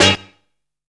KING STAB.wav